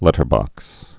(lĕtər-bŏks)